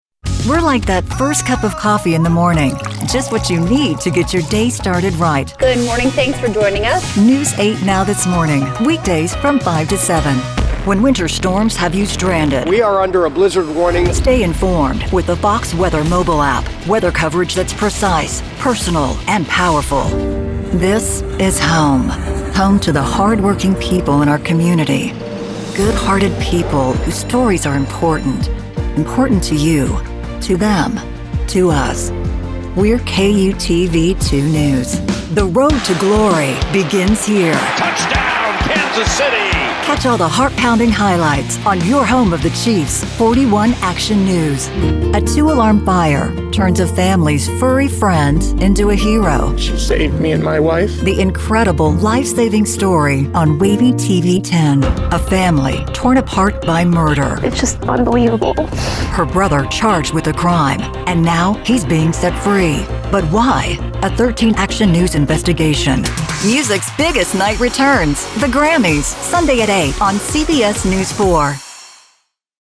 Warm, Confident, Elegant.
TV Promo